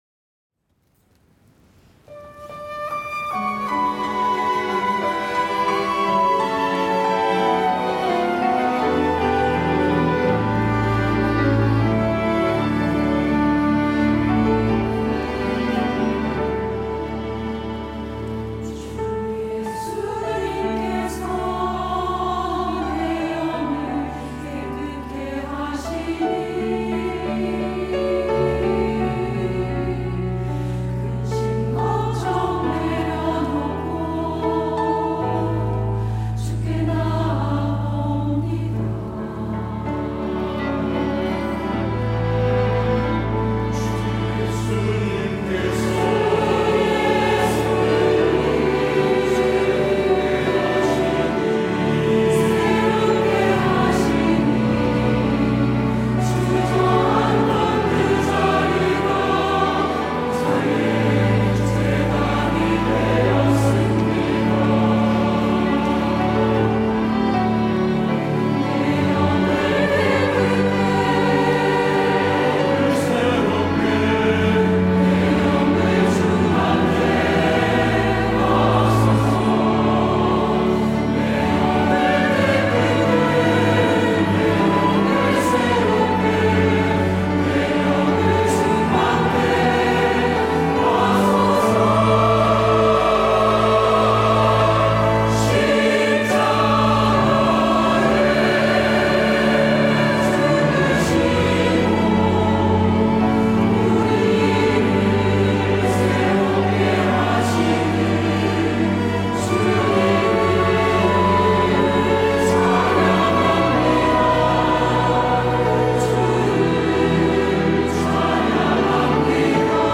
호산나(주일3부) - 주께서 내 영을
찬양대